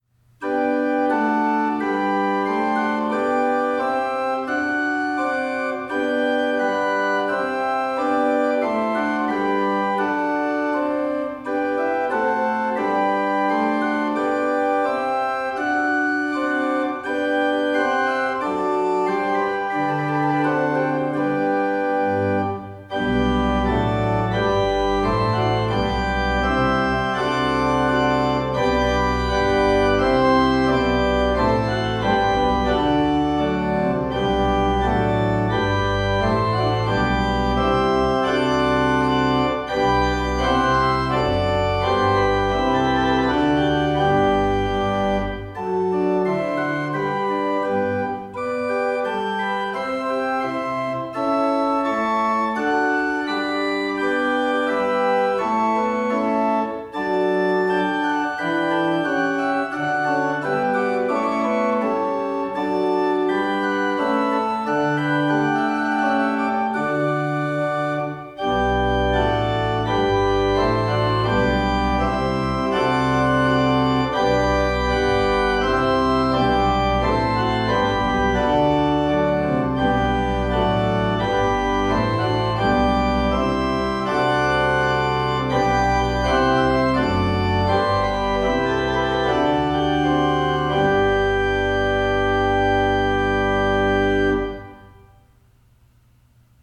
We’ve prepared this page to help you choose organ music for your wedding ceremony.
C. Light and Cheeful